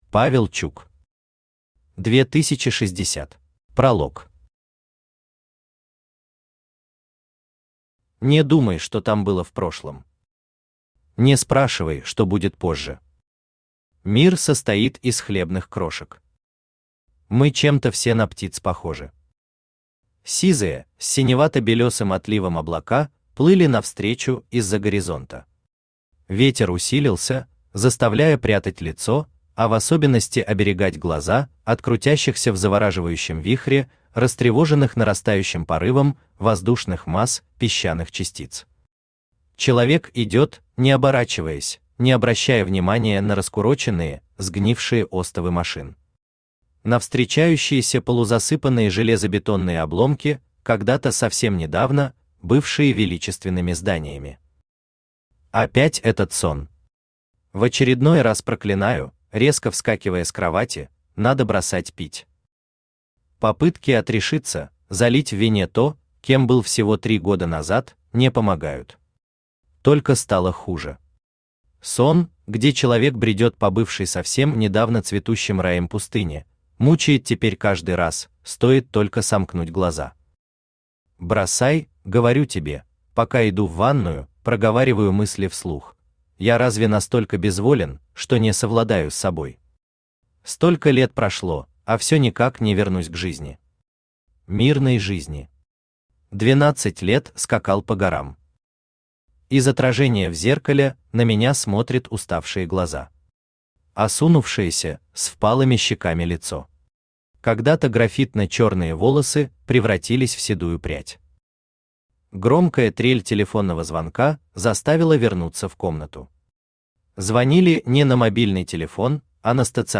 Аудиокнига 2060 | Библиотека аудиокниг
Aудиокнига 2060 Автор Павел Чук Читает аудиокнигу Авточтец ЛитРес.